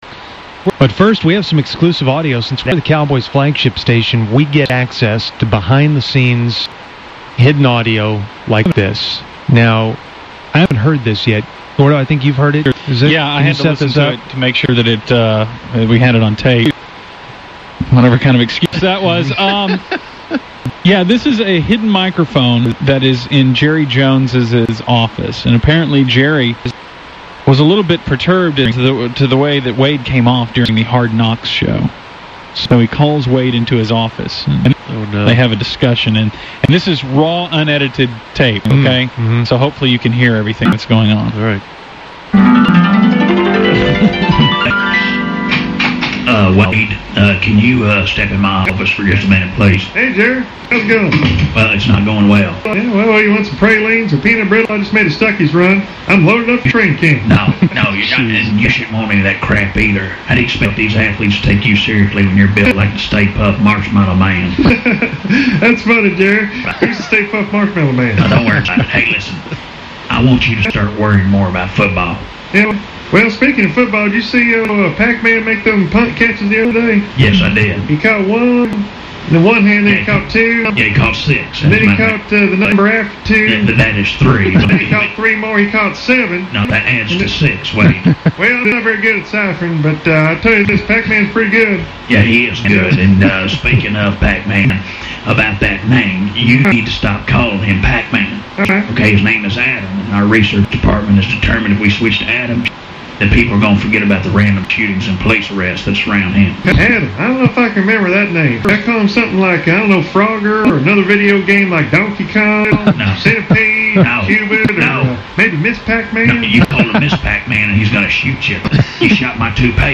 Supposedly The Musers had hidden microphones that captured this exclusive audio.